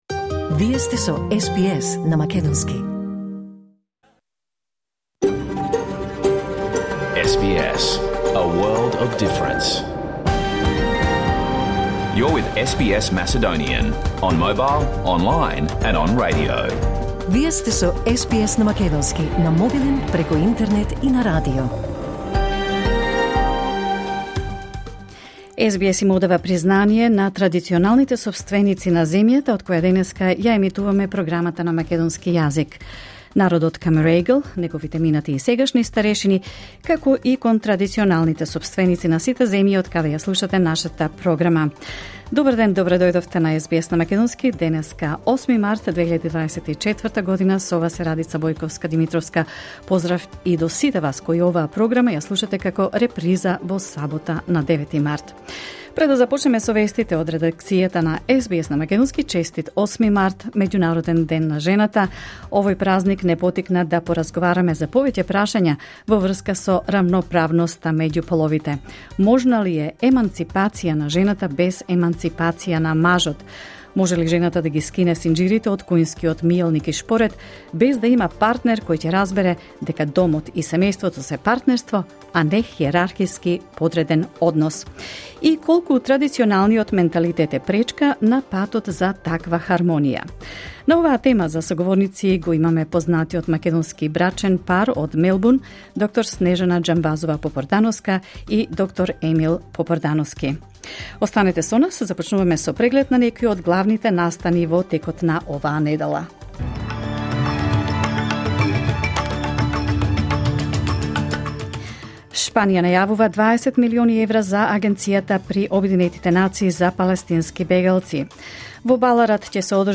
SBS Macedonian Program Live on Air 8 March 2024